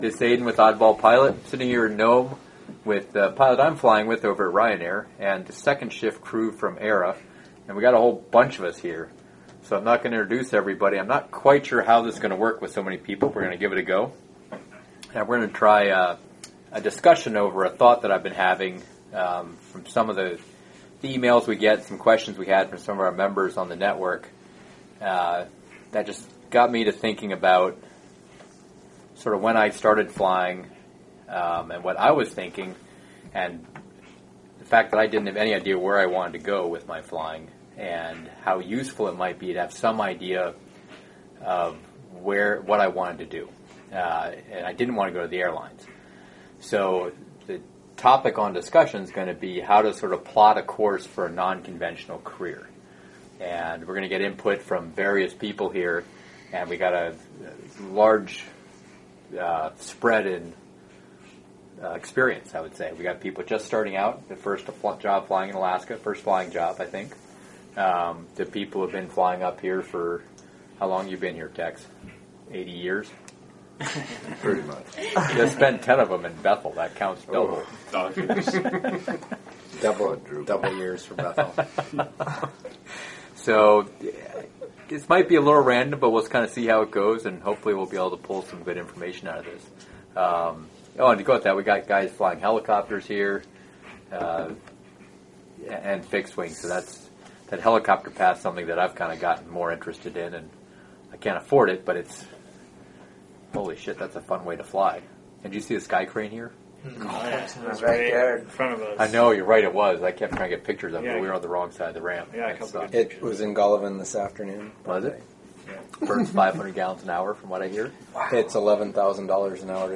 Some pizza and beer got folks talking.
plotting_an_unconventional_flying_career_discussion.mp3